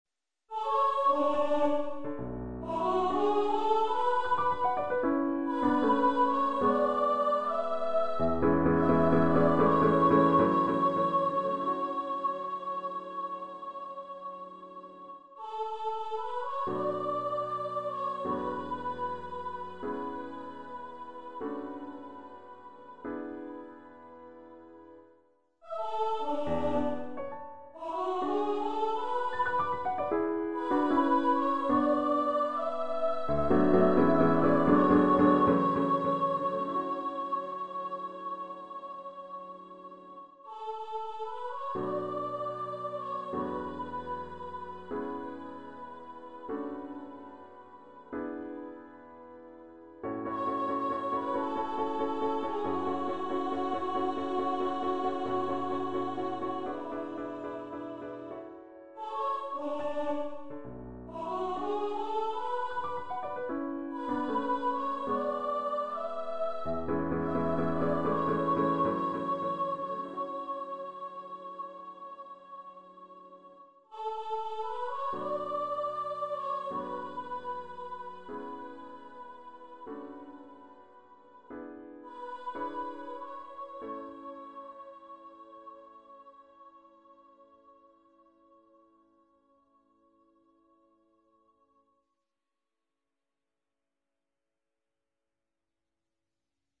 Composer's Demo